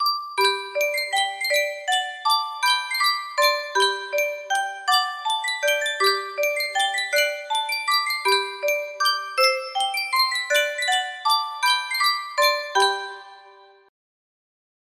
Sankyo Spieluhr - Le vieux chalet GCS music box melody
Full range 60